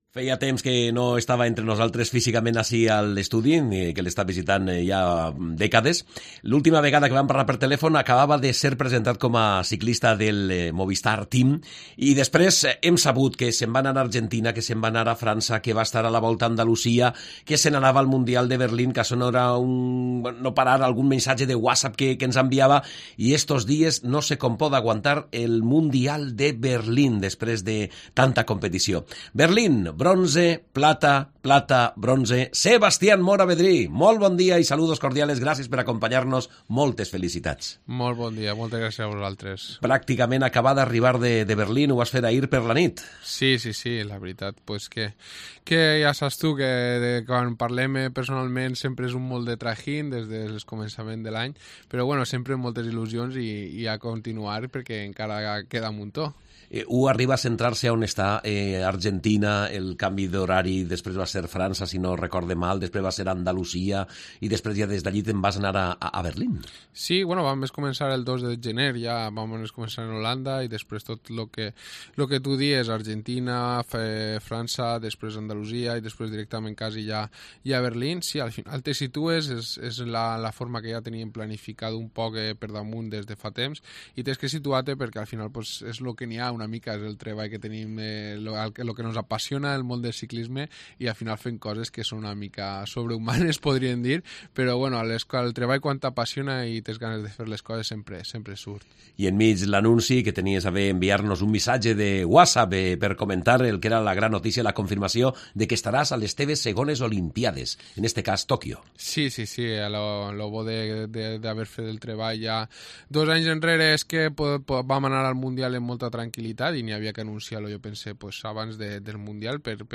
Entrevista en Mediodía COPE Más Castellón al ciclista Sebastián Mora